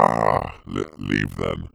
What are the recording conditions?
Update Voice Overs for Amplification & Normalisation